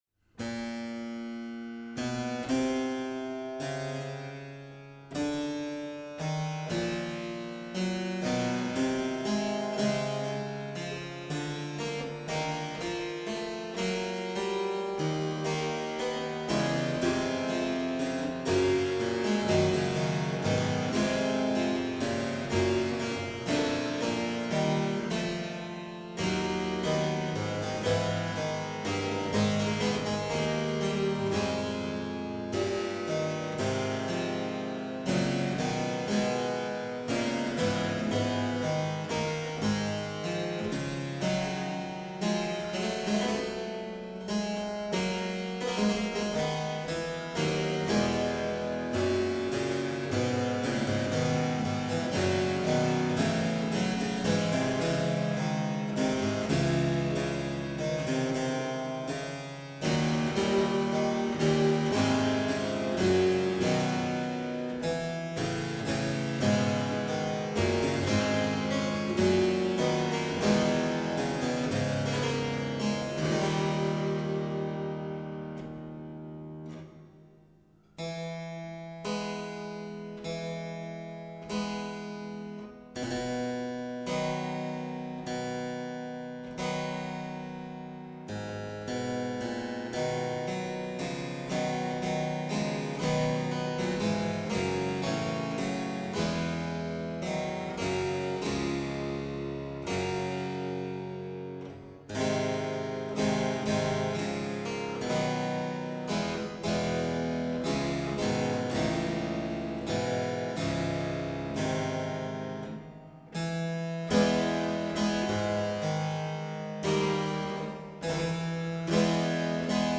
The disc contained a performance of what is possibly the first and probably the most famous chromatic part-song of the 16th century, Cipriaan de Rore�s Calami sonum ferentes . The recording was lugubrious to a degree, to the exclusion of all other possible musical values.